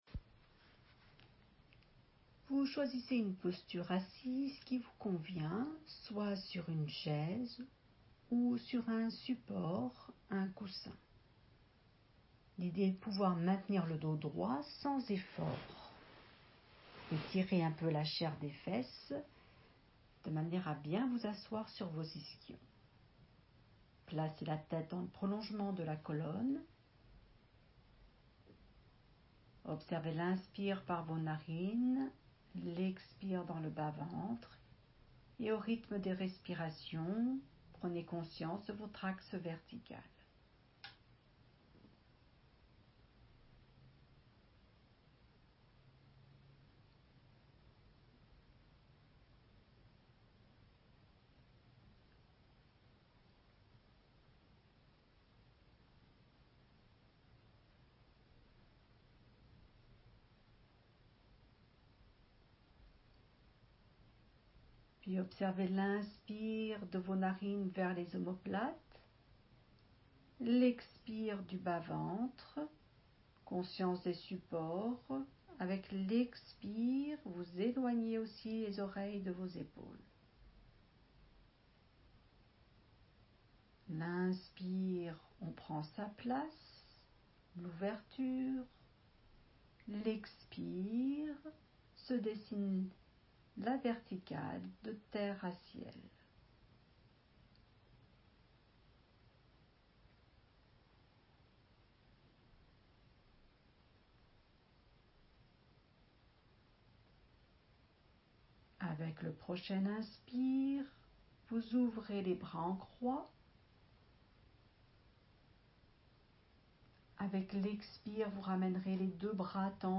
La séance guidée